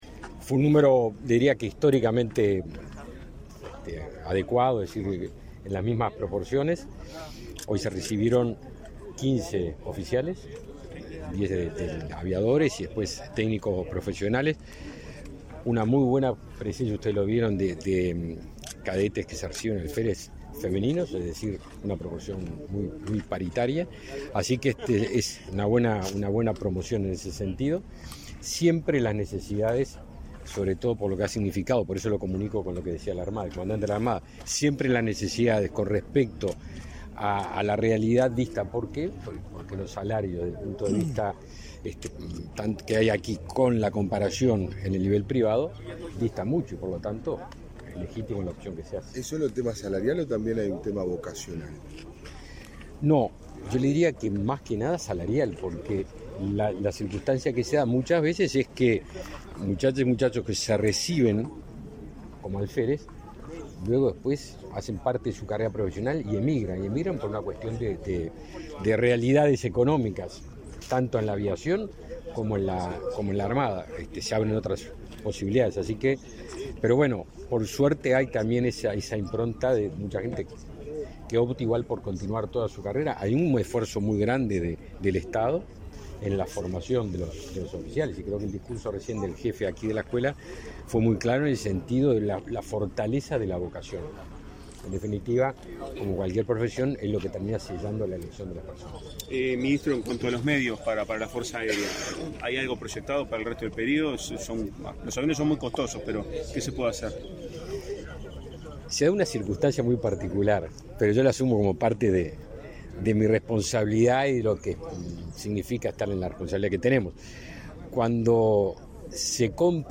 Declaraciones a la prensa del ministro de Defensa Nacional, Javier García
Declaraciones a la prensa del ministro de Defensa Nacional, Javier García 14/12/2022 Compartir Facebook X Copiar enlace WhatsApp LinkedIn Con la presencia del presidente de la República, Luis Lacalle Pou, se realizó, este 14 de diciembre, la ceremonia de clausura de cursos y colación de grados 2022. Tras el evento, el ministro de Defensa Nacional, Javier García, realizó declaraciones a la prensa.